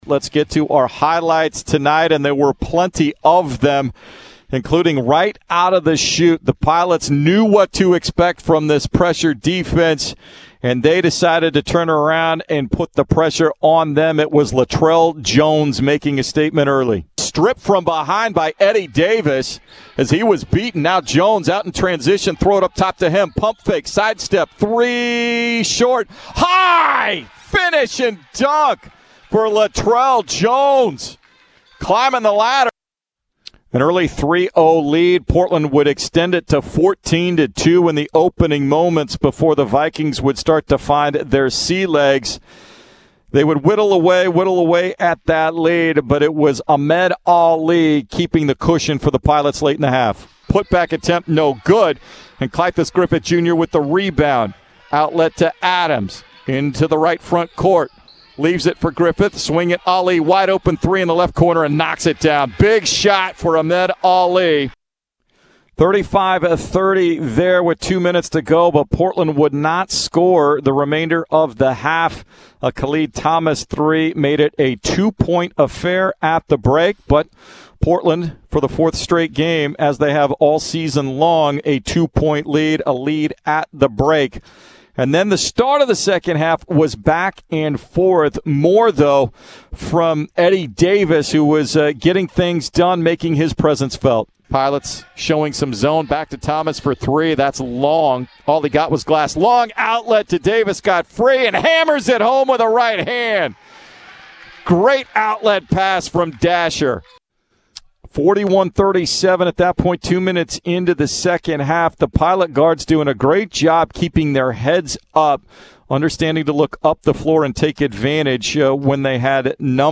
December 05, 2020 Highlights from Portland's 86-73 win over crosstown rival Portland State on Saturday, Dec. 5 at the Chiles Center. Courtesy of 910 ESPN-Portland (KMTT)